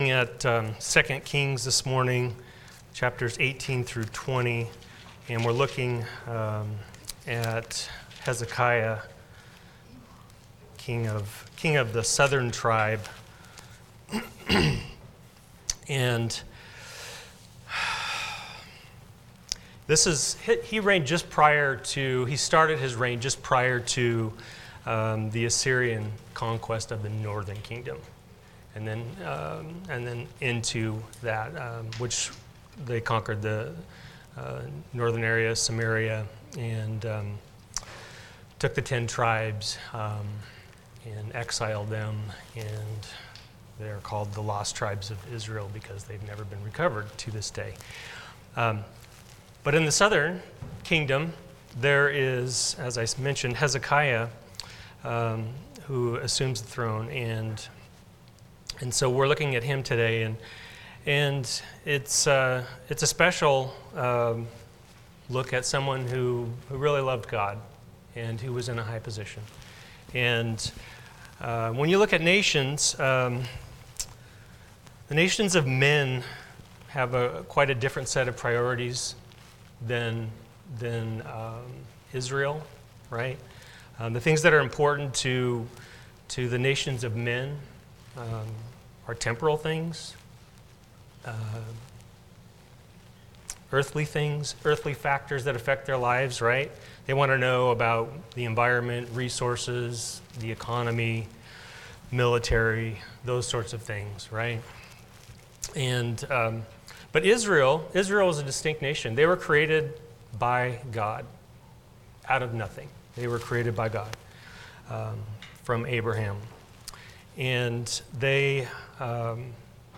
Passage: 2 Kings 18-20 Service Type: Sunday School